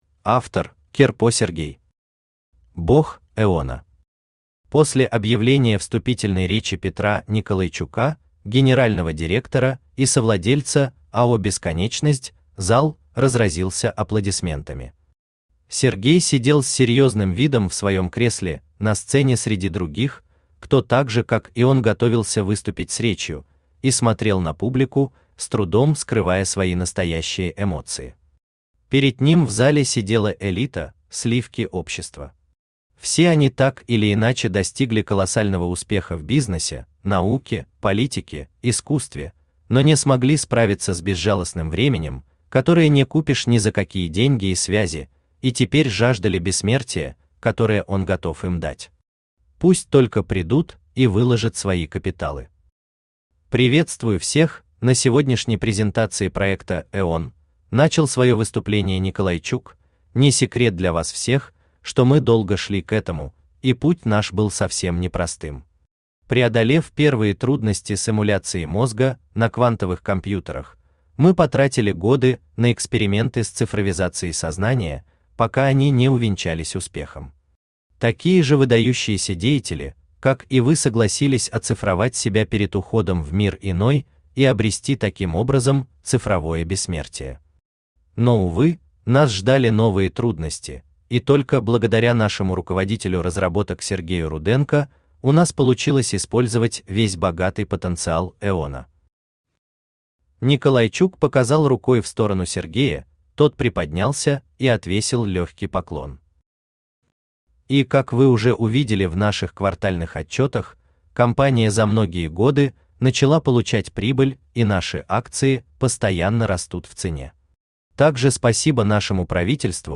Aудиокнига Бог Эона Автор Сергей Валерьевич Кирпо Читает аудиокнигу Авточтец ЛитРес. Прослушать и бесплатно скачать фрагмент аудиокниги